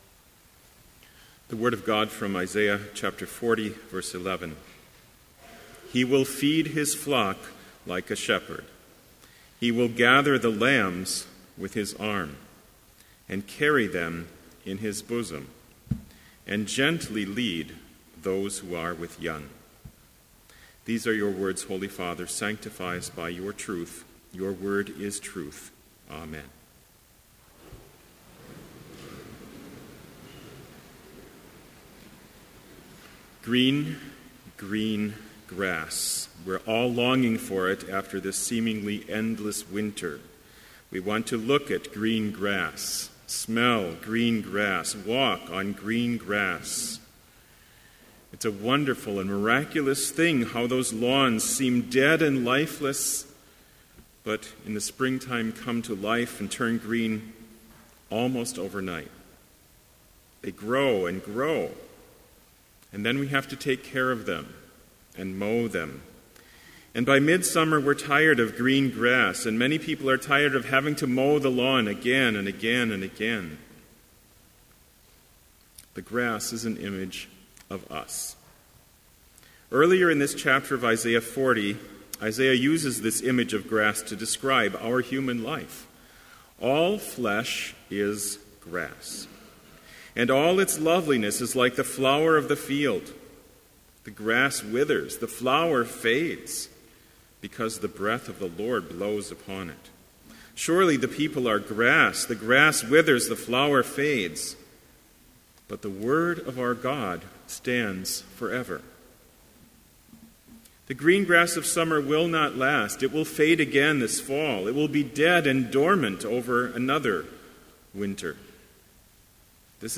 Complete Service
• Homily
This Chapel Service was held in Trinity Chapel at Bethany Lutheran College on Wednesday, April 22, 2015, at 10 a.m. Page and hymn numbers are from the Evangelical Lutheran Hymnary.